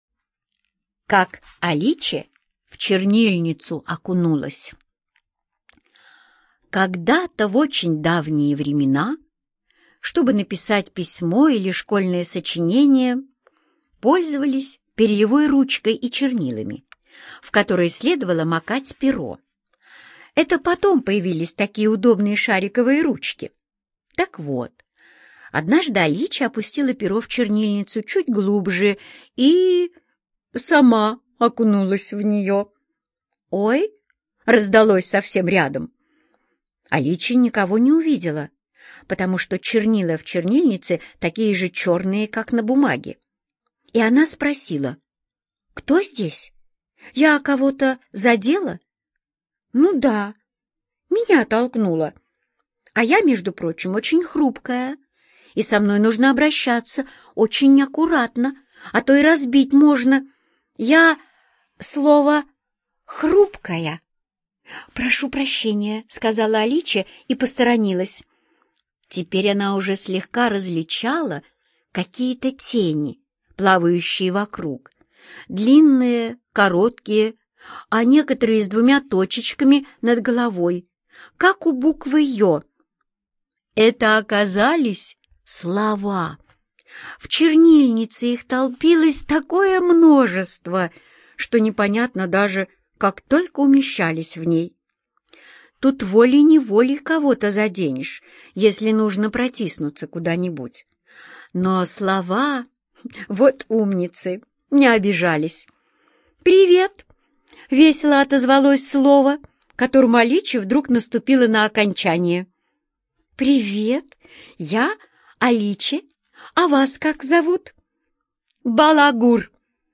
Слушать Как Аличе в чернильницу окунулась - аудиосказку Родари Д. Однажды Аличе случайно упала в старинную чернильницу, где жили слова.